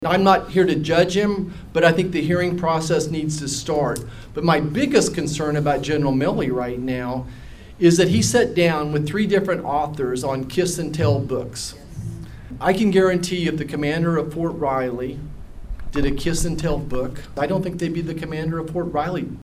Senator Roger Marshall spoke to members of the community during his town hall over the weekend.